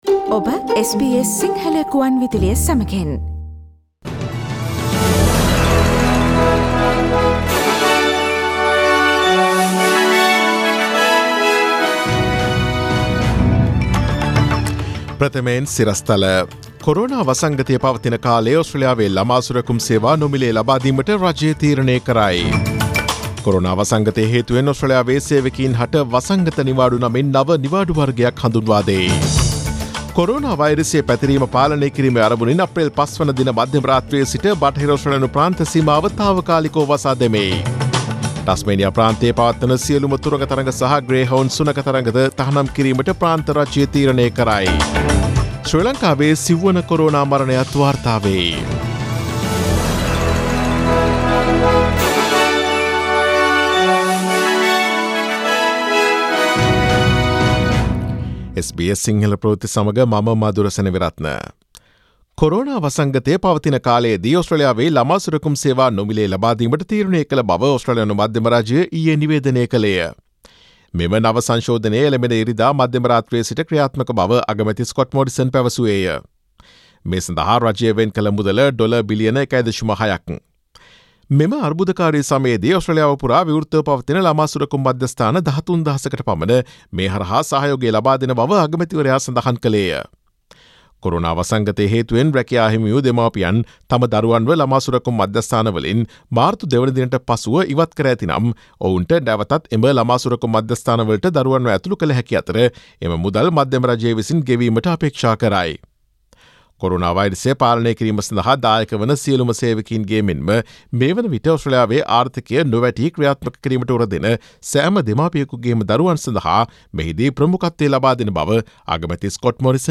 Daily News bulletin of SBS Sinhala Service: Friday 03 April 2020
Today’s news bulletin of SBS Sinhala Radio – Friday 03 April 2020 Listen to SBS Sinhala Radio on Monday, Tuesday, Thursday and Friday between 11 am to 12 noon